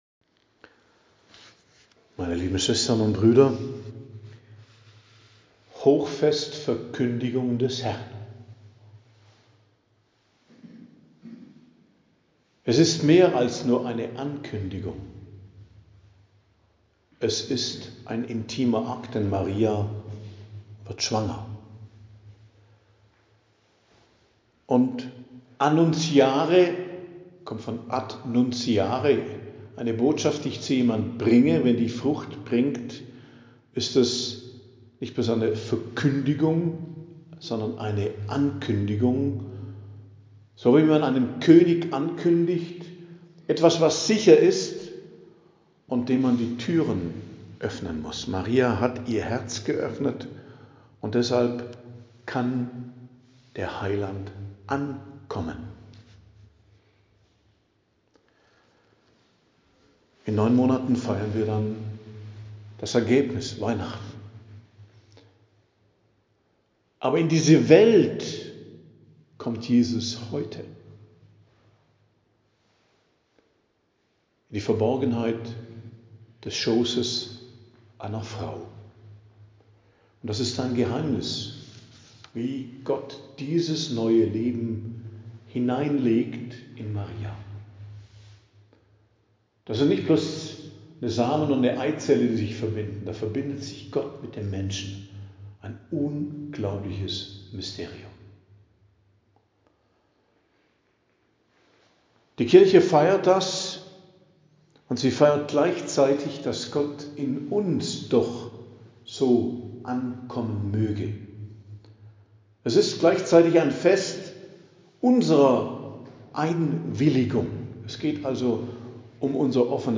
Predigt am Hochfest Verkündigung des Herrn, 25.03.2025 ~ Geistliches Zentrum Kloster Heiligkreuztal Podcast